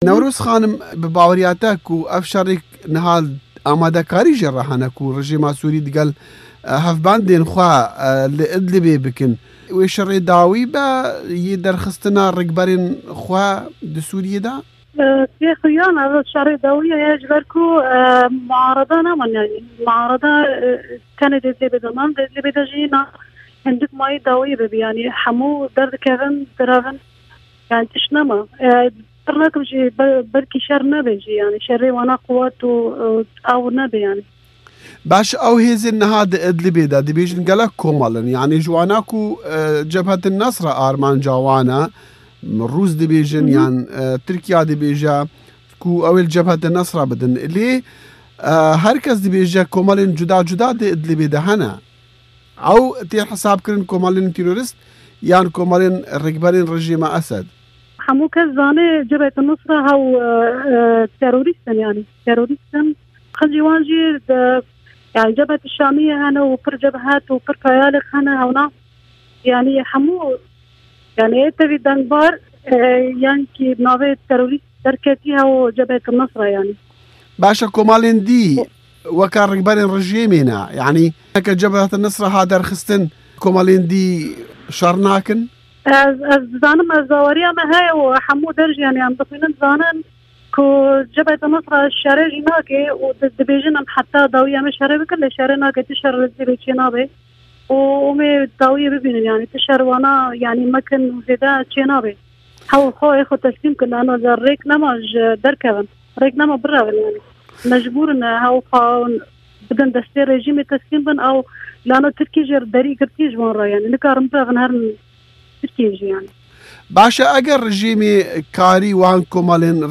Di hevpeyvînekê de bi Dengê Amerîka VOA re